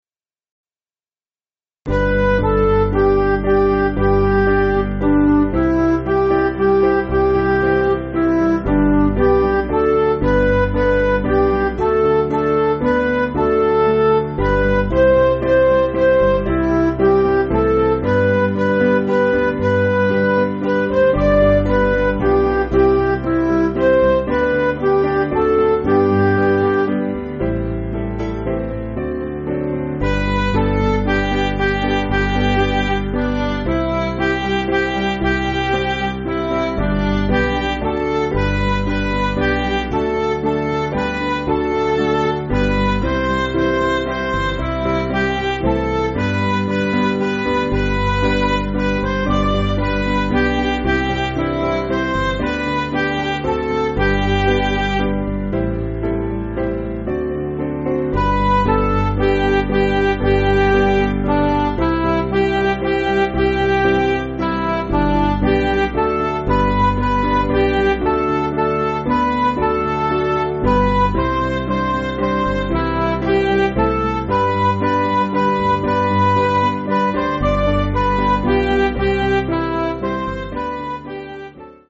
Piano & Instrumental
(CM)   4/G